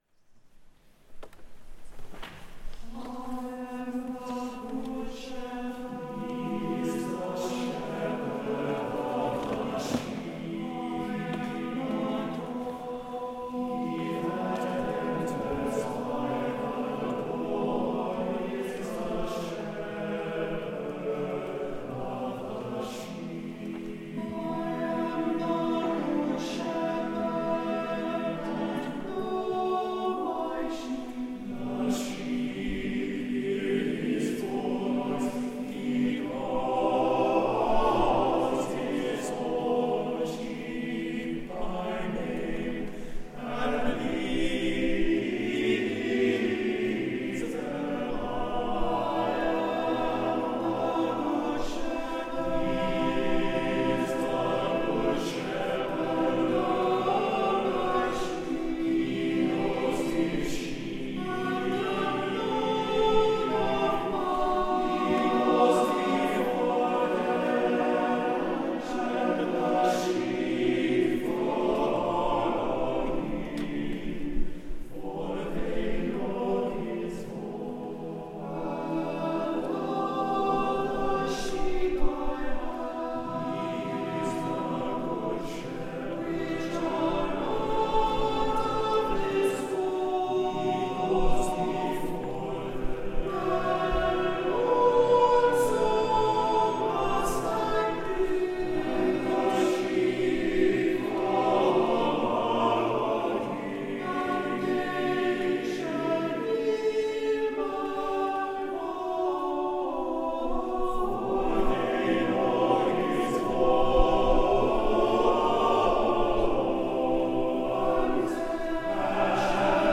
As we begin to wrap up our second week of pilgrimage to Canterbury and Salisbury Cathedrals, we’d like to share some audio selections from Canterbury Cathedral during evensong services sung last week, including the Office of Compline as performed in the crypt: